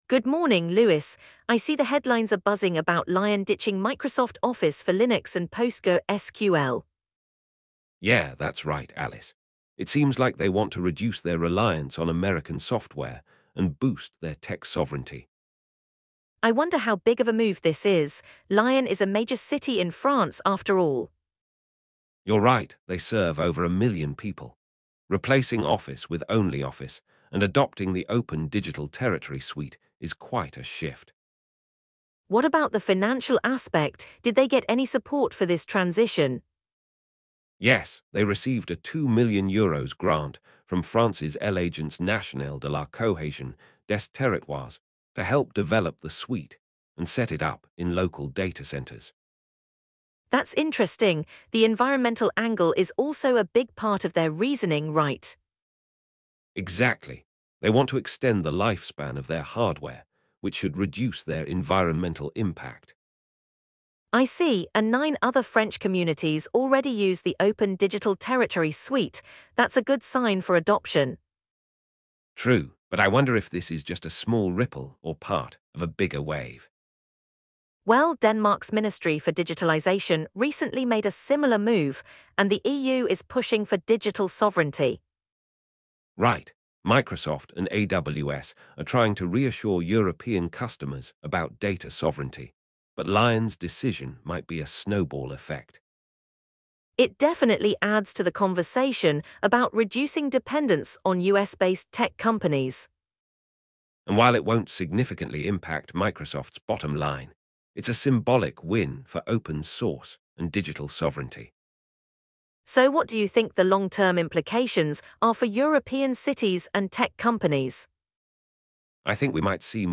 The conversation also touches on similar moves in Denmark and the broader implications for European cities and tech companies.